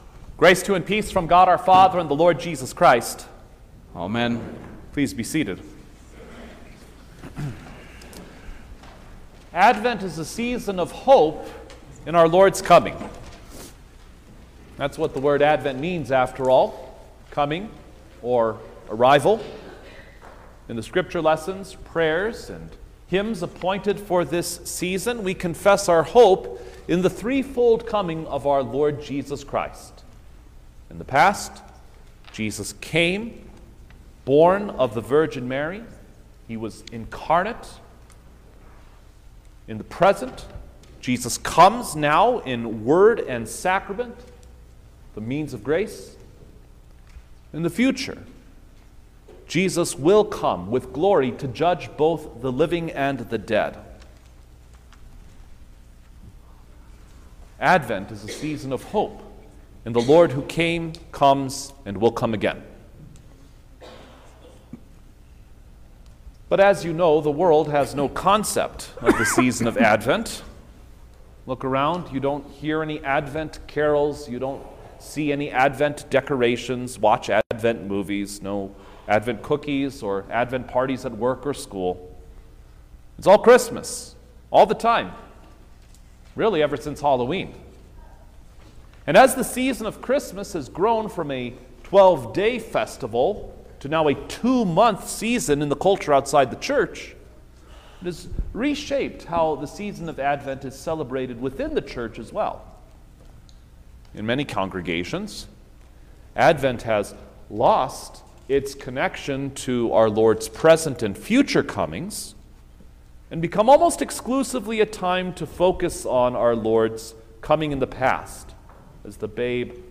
December-8_2024_Seconsd-Sunday-in-Advent_Sermon-Stereo.mp3